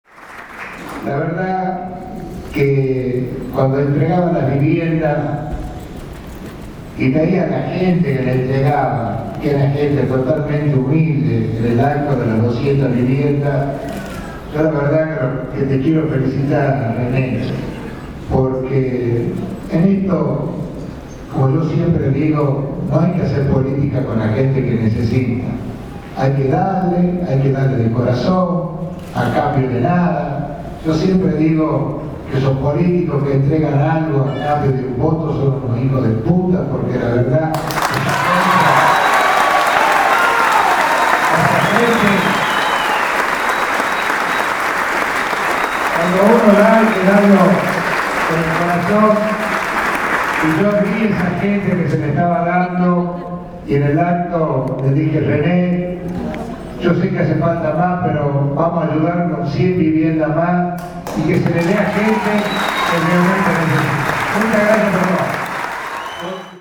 El gobernador cerró con estas palabras un acto en el Salón Blanco, en donde anunció viviendas para los afiliados al gremio de ATSA.
En un salón colmado, Alperovich se refirió en su discurso sobre la importancia del otorgamiento de casas a quienes más lo necesitan.
El audio del exabrupto de Alperovich en un acto - MP3